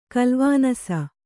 ♪ kalvānasa